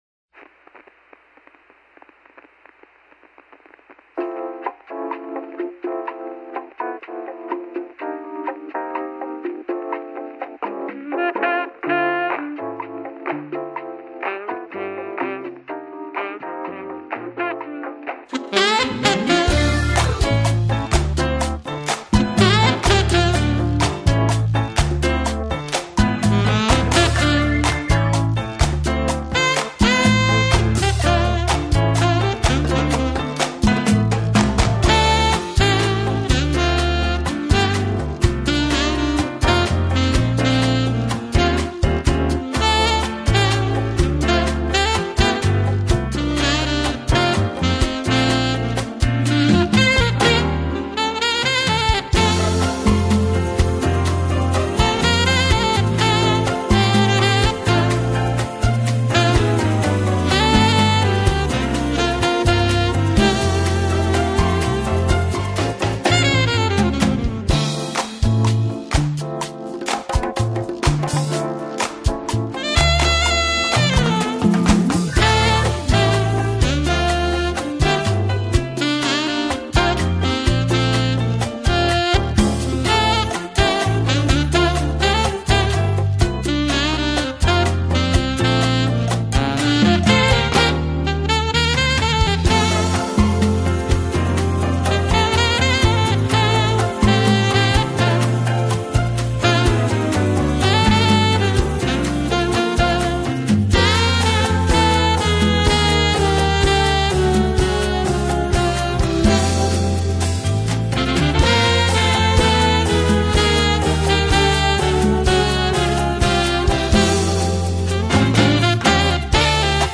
alto/soprano saxophonist
bassist
keyboardist
tart and tight sax tones and pithy flute solos
Latin-tinged number
" retains its Spanish Harlem swing